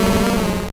Cri d'Ossatueur dans Pokémon Or et Argent.